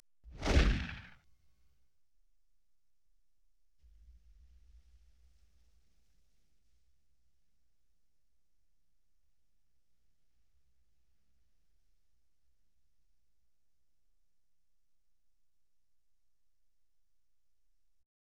5.1 Explosion Set 10 - RS.wav